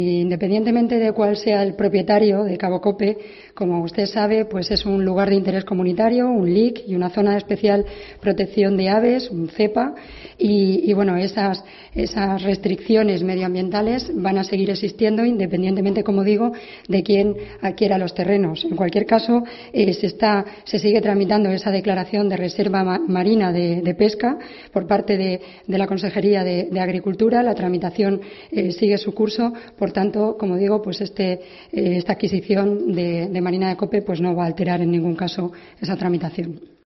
Ana Martínez Vidal, portavoz gobierno regional